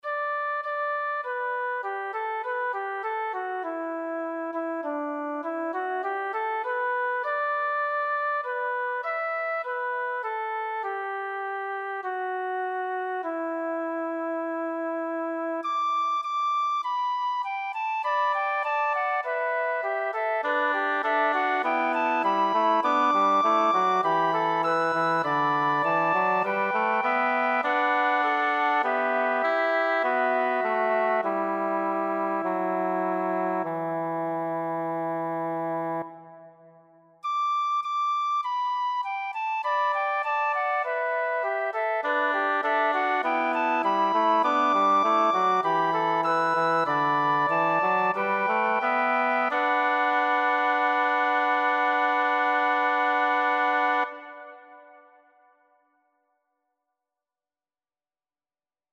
2º Música Invierno, 2º Música Periodos, 3º Música Flauta, 3º Música Invierno | 0 Comentarios